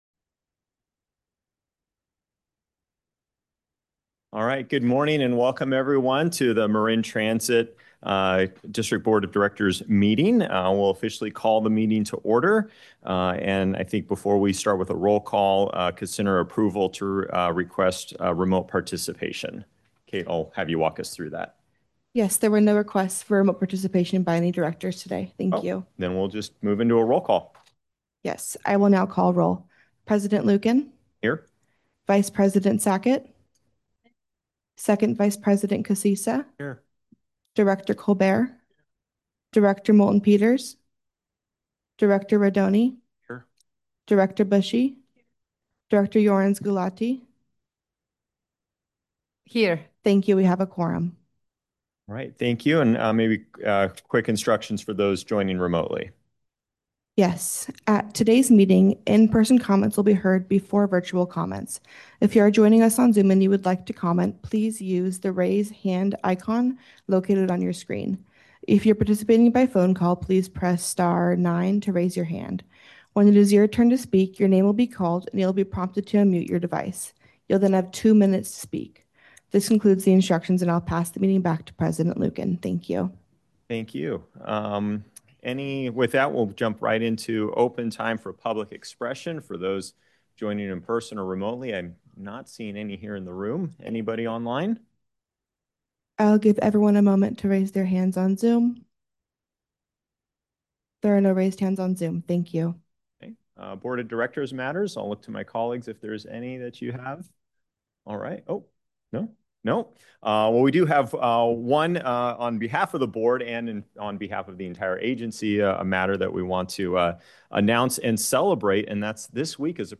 November 2025 Board of Directors Meeting
Members of the public can provide comment during open time and on each agenda item when the Board President calls for public comment. In-person comments will be heard before virtual comments.